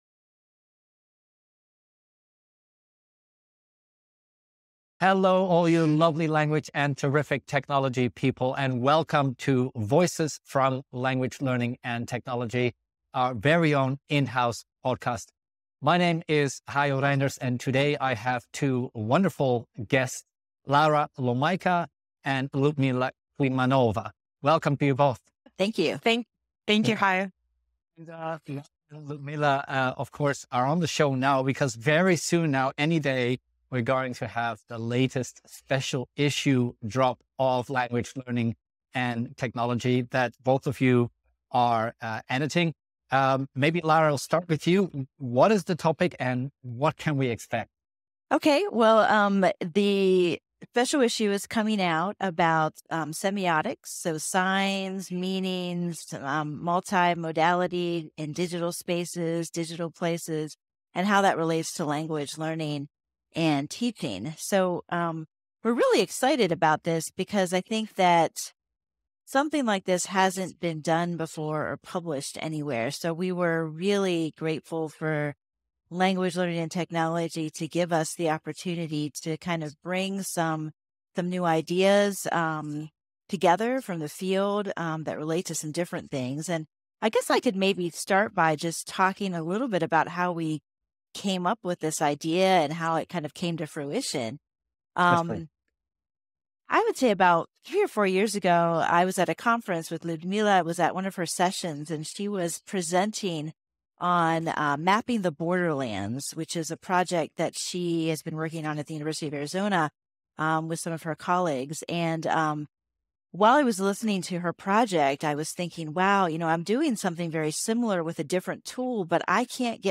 A conversation
interviews the guest editors of a Special Issue on Semiotics